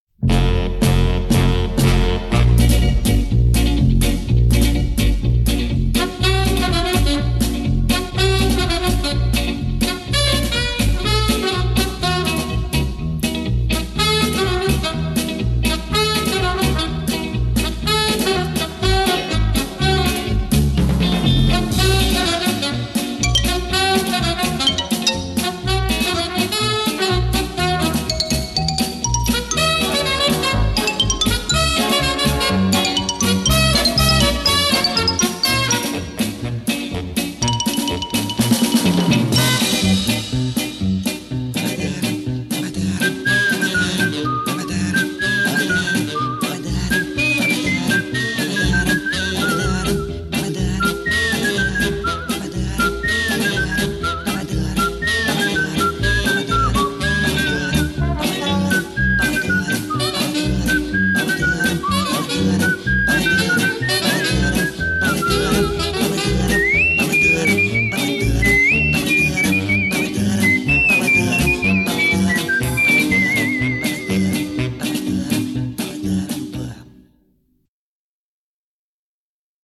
Музыка к кинофильмам